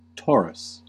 Ääntäminen
IPA : /ˈtɔːrəs/